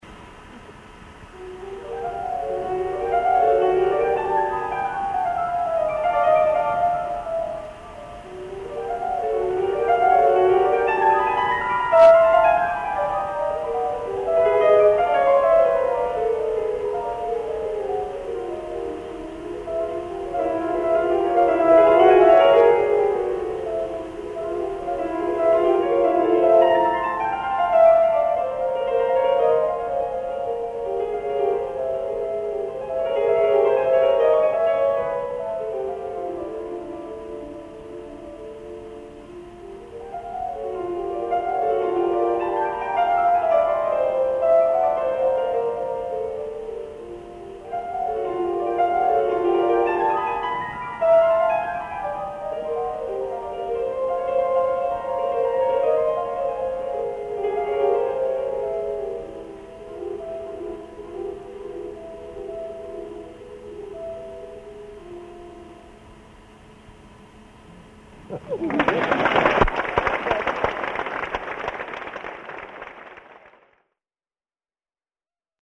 Piano–live recording of an encore